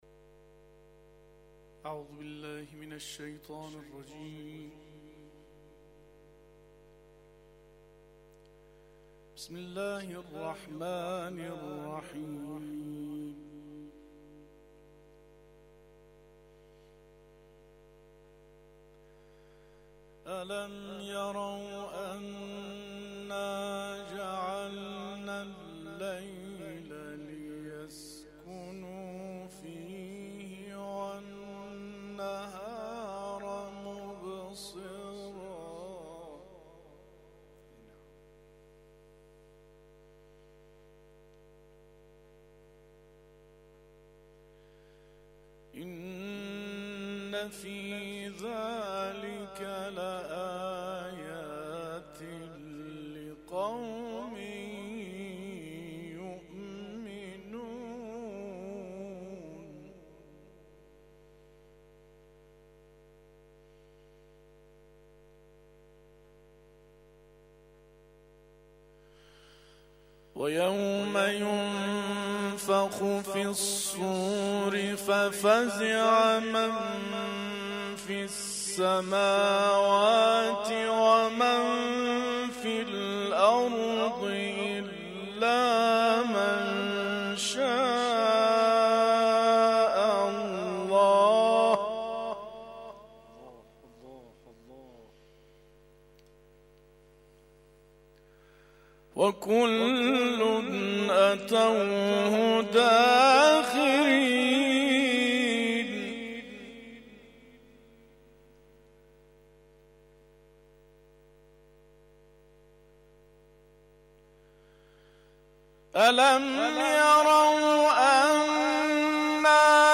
تلاوت ظهر
تلاوت مغرب